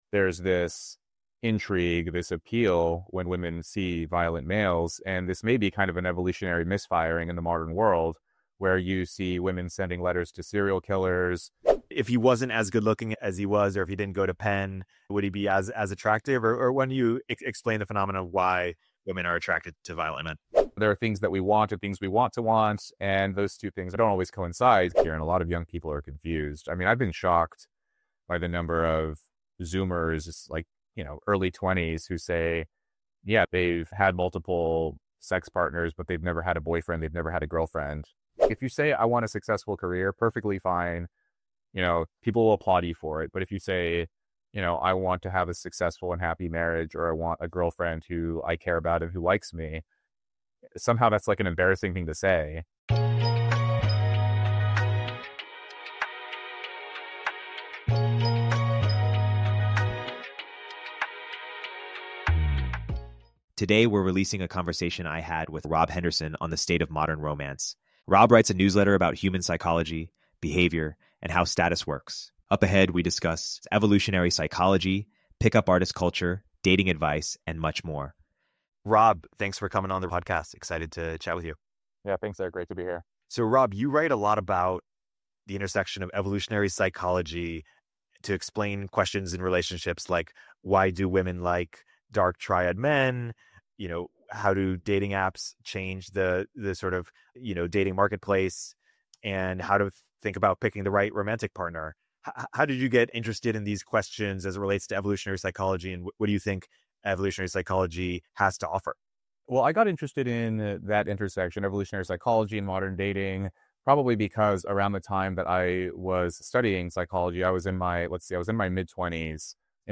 "Modern Relationships" chevron_right How to Be Attractive & What Data Says About Dating | Rob Henderson Apr 1, 2025 auto_awesome Rob Henderson, a best-selling author, Air Force veteran, and psychologist, dives into the intriguing world of modern dating and attraction. He discusses how evolutionary psychology explains women's interest in men with dark triad traits, shedding light on the paradox of aggression enhancing appeal. The conversation covers pickup artist culture and the evolving dynamics of dating apps, emphasizing the importance of emotional cues and genuine character development for building meaningful relationships.